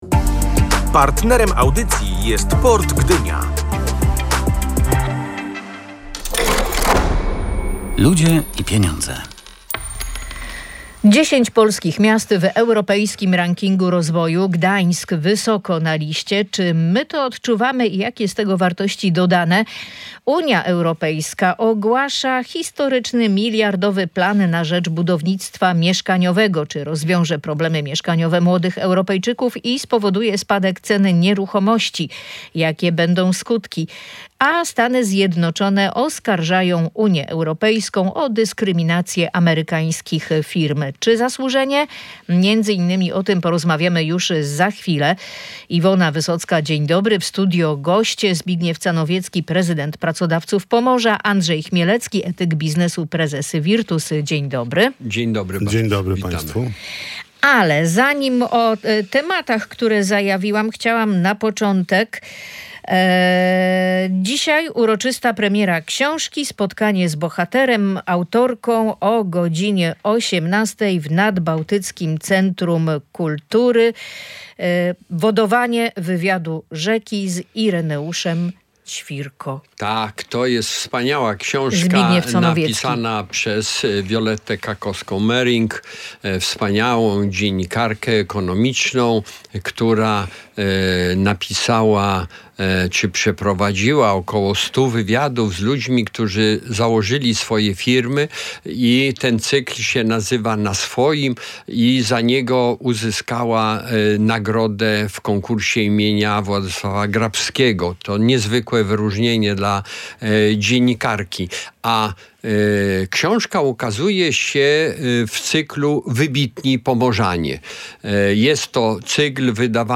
Eksperci komentują